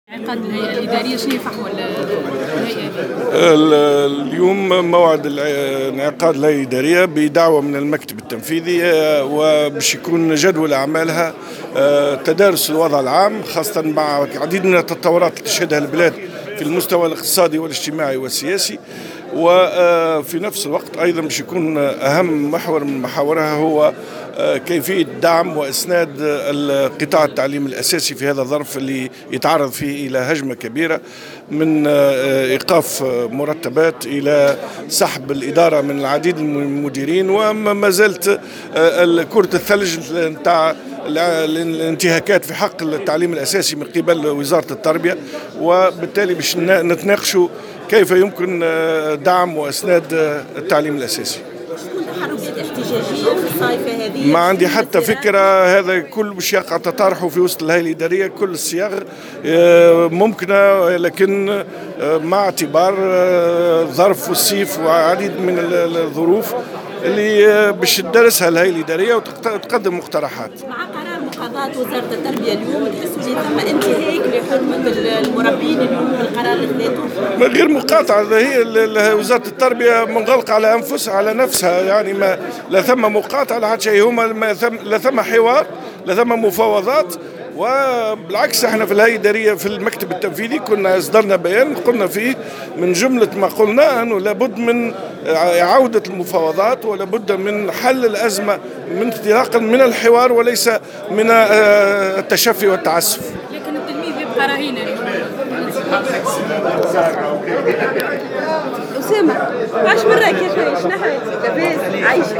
Comité administratif national: Le dossier de l’enseignement de base sur la table de l’UGTT [Déclaration]